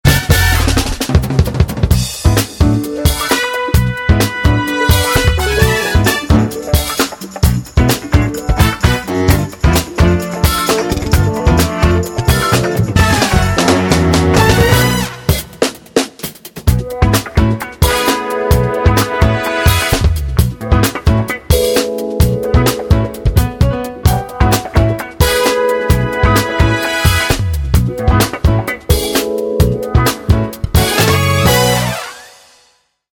Mit knackigen Bläsern geht immer.